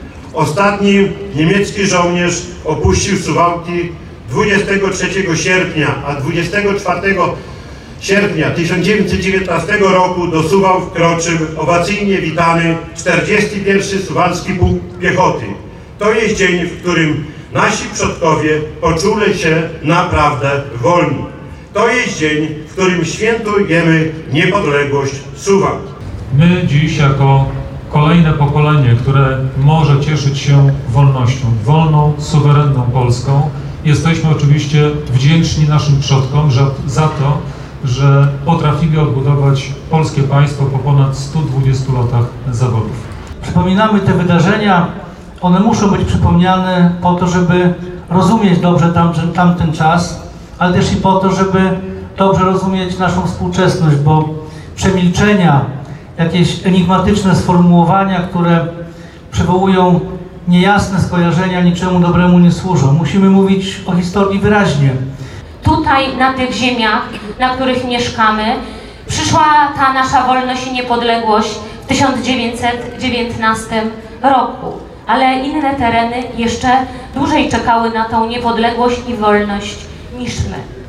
Głos zabrali między innymi Czesław Renkiewicz, prezydent Suwałk, Dariusz Piontkowski, minister edukacji, Jarosław Zieliński, wiceminister MSWiA oraz Bożena Kamińska, posłanka Platformy Obywatelskiej.
przmeówienia.mp3